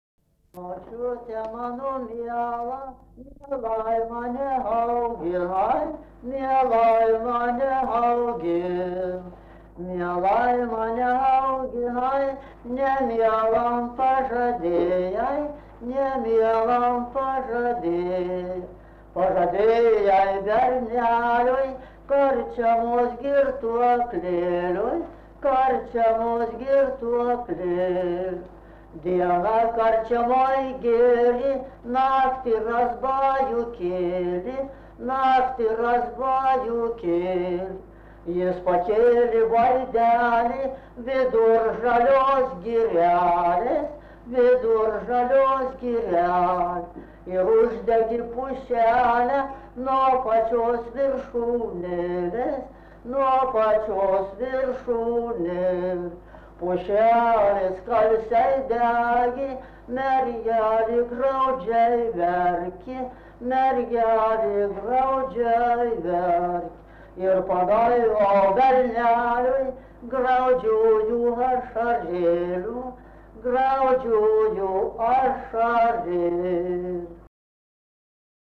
daina
Menčikiai
vokalinis